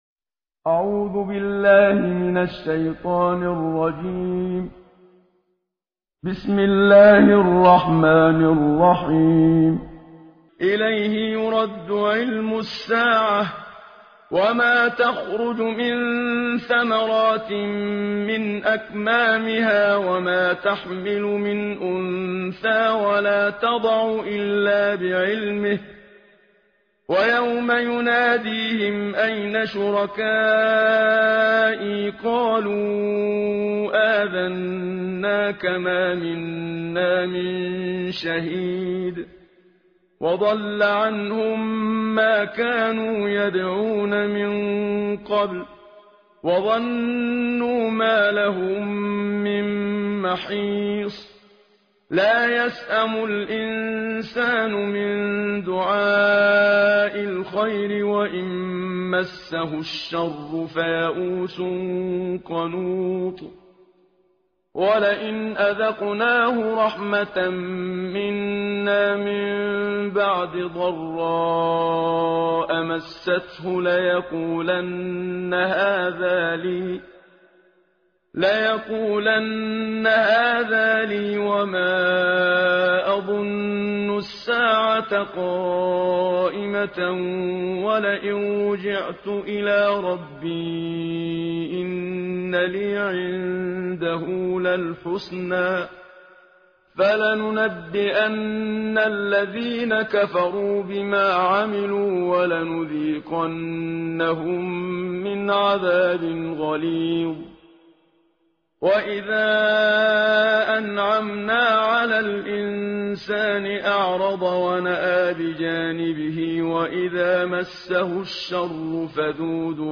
ترتیل جزء بیست و پنجم قرآن با صدای استاد منشاوی
تهران- الکوثر: در بیست و پنجمین روز ماه مبارک رمضان، تلاوت جزء بیست و پنجم قرآن کریم را با صدای قاری مشهور مصری استاد محمد صدیق منشاوی، با هم می شنویم.